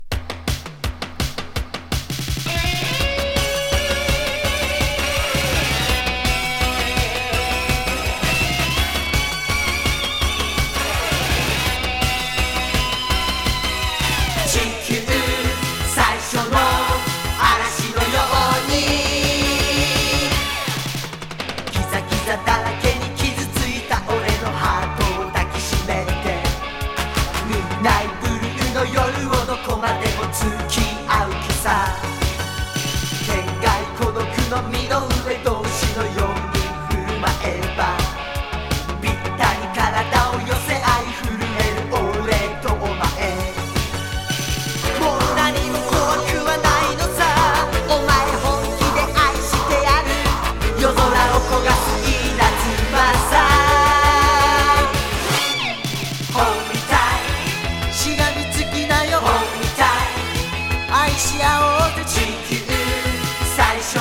男性アイドルトリオ